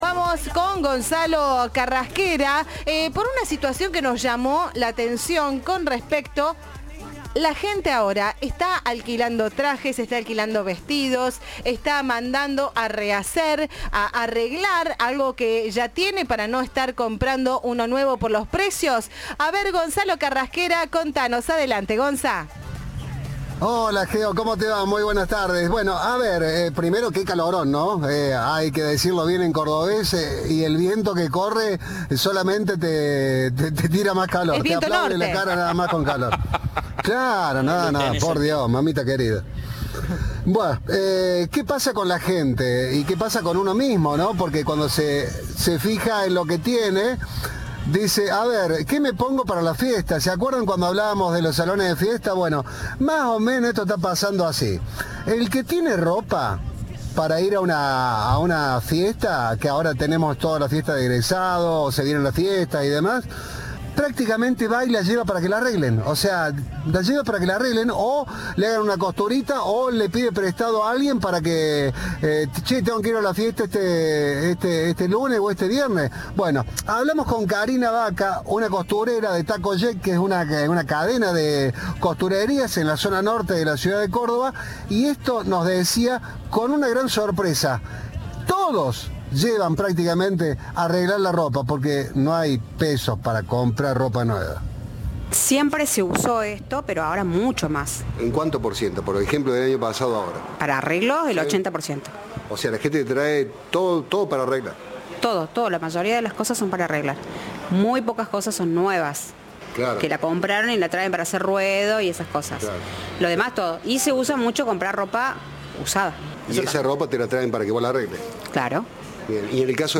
En una recorrida por el centro de Córdoba Cadena 3 confirmó la tendencia de alquilar ropa de fiesta, arreglar alguna prenda usada o comprar algo de segunda mano.
Informe